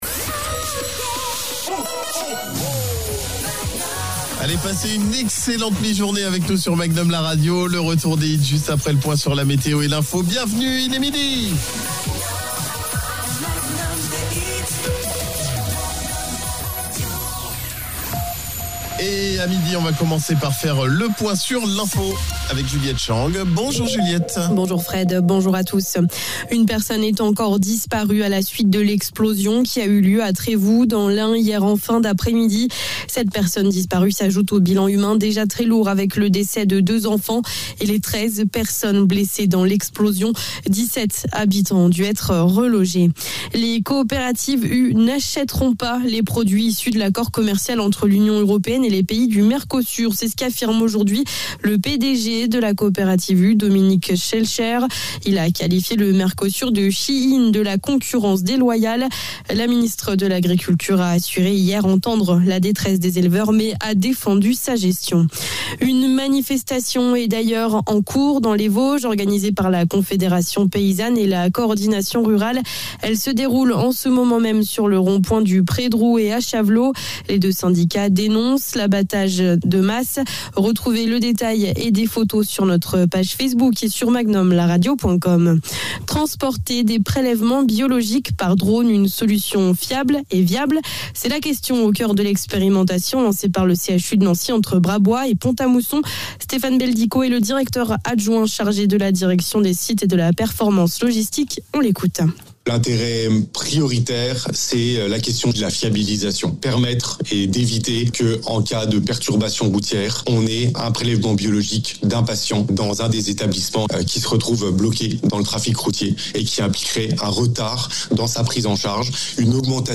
flash infos